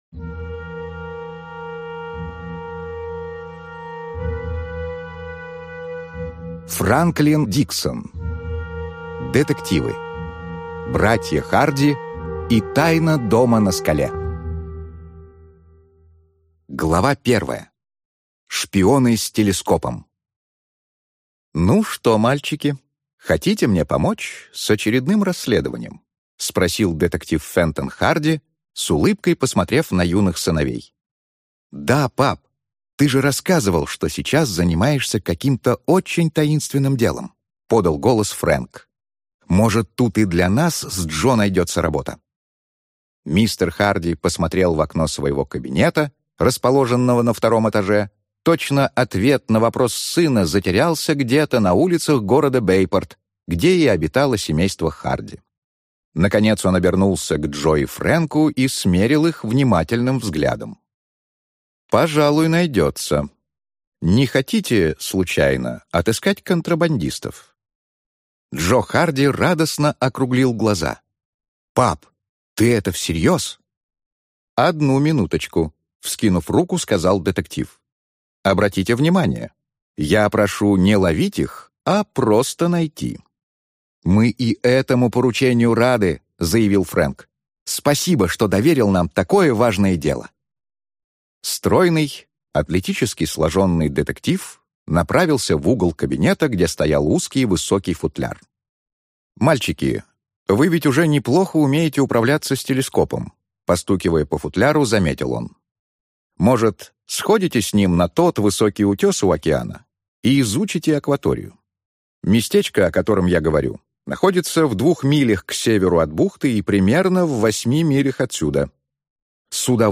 Аудиокнига Братья Харди и тайна дома на скале | Библиотека аудиокниг